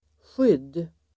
Uttal: [sjyd:]